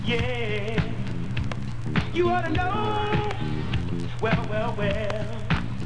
Media experiments with the SoundScriber Record Embosser.
For those of you who do not know what a SoundScriber is, it is a monophonic record lathe.
Aluminum Hard Drive Platter
Hard Drive Platters: These provide the widest frequency response and highest signal to noise ratio of anything I tried.
Sound Quality Demonstration
platter.wav